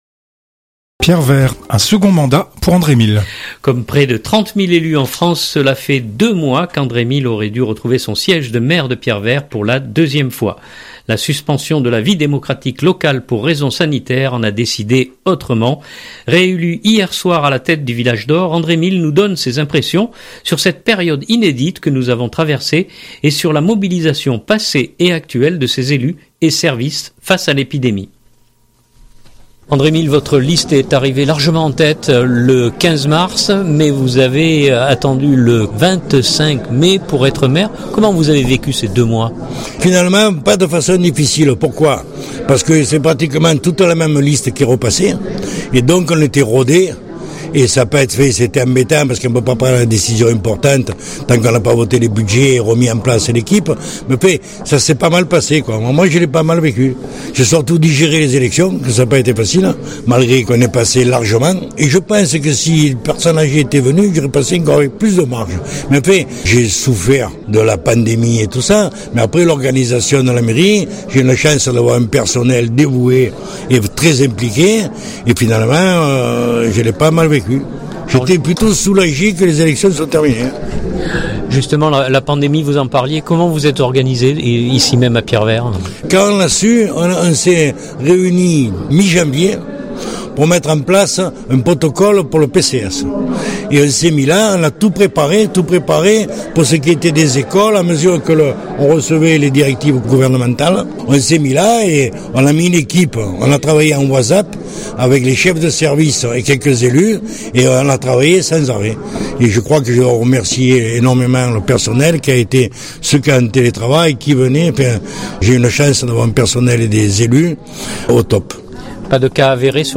Réélu hier soir à la tête du Village d’Or, André Mille nous donne ses impressions sur cette période inédite que nous avons traversée et sur la mobilisation passée et actuelle de ses élus et services face à l’épidémie.